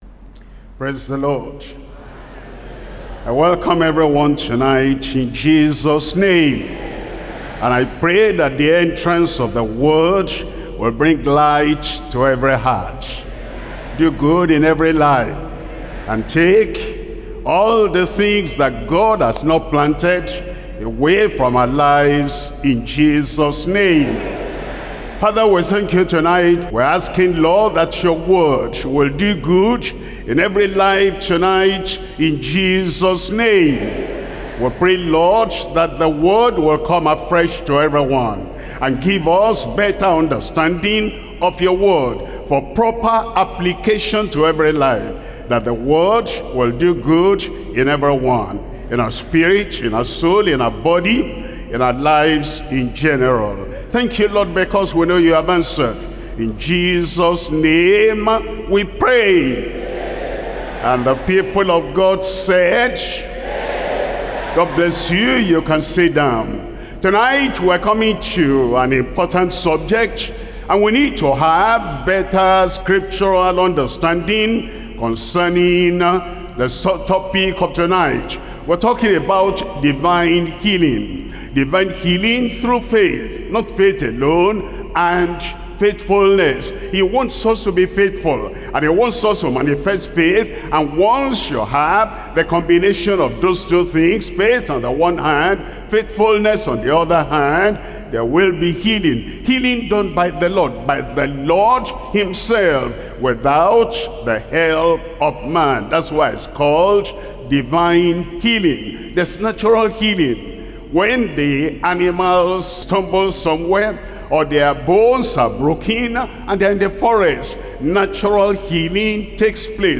Sermons – Deeper Christian Life Ministry, United Kingdom